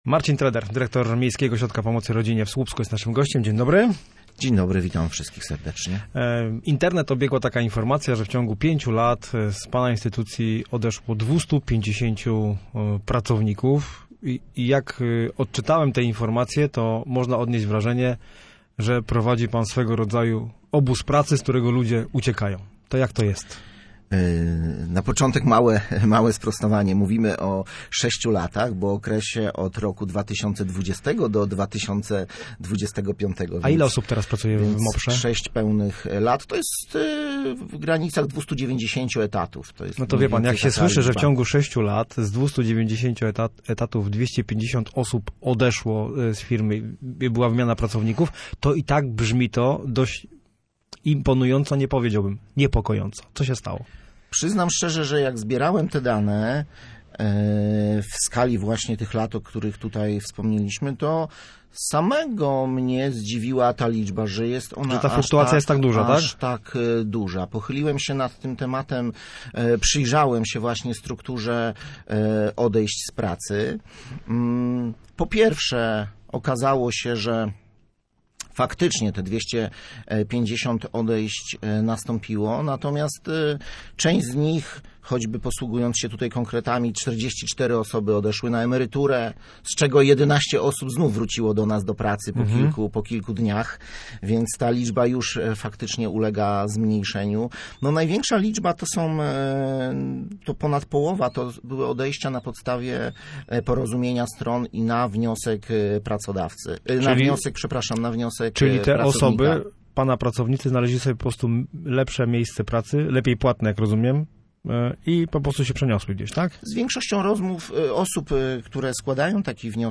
Studio Słupsk Radia Gdańsk